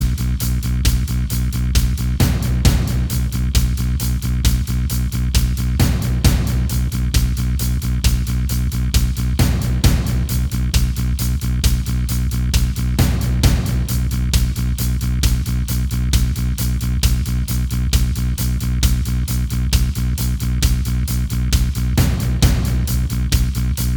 Minus Guitars Rock 4:55 Buy £1.50